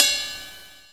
Closed Hats
Wu-RZA-Hat 8.wav